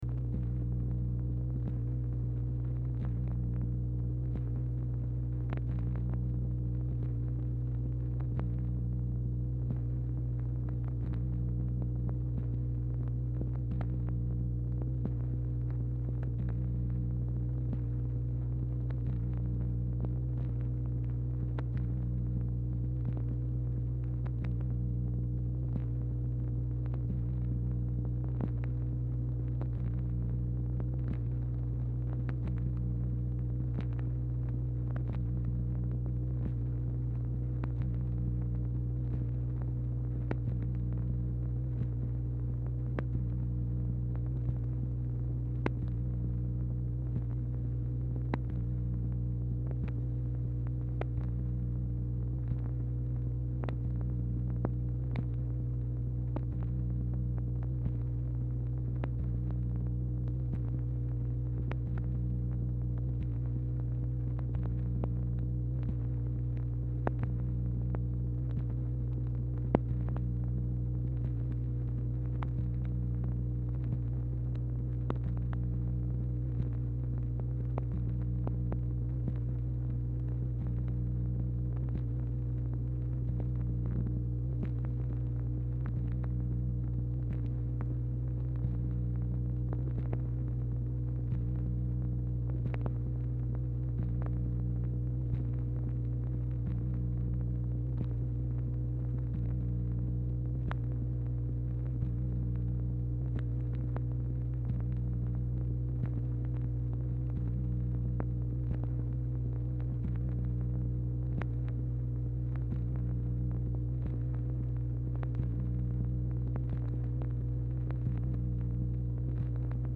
Telephone conversation # 1140, sound recording, MACHINE NOISE, 1/1/1964, time unknown | Discover LBJ
Format Dictation belt
Speaker 2 MACHINE NOISE